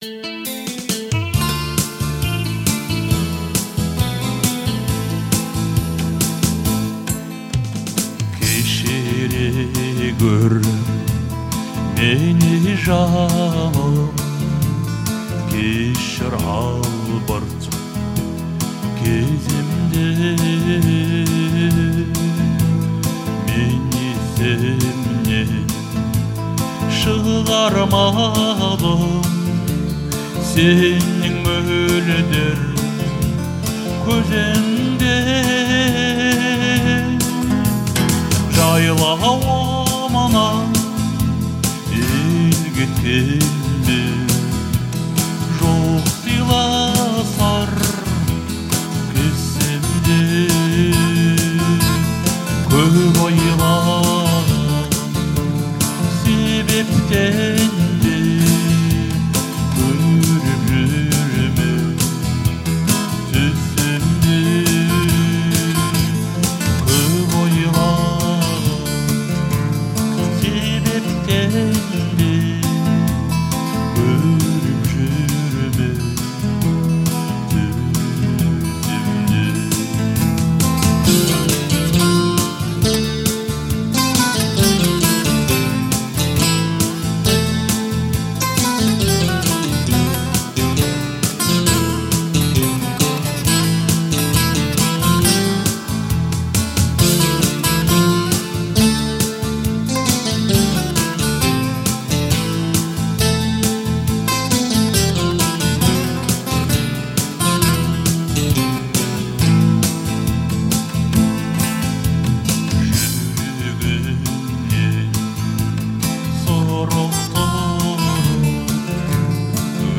его голос наполняет каждую ноту искренностью.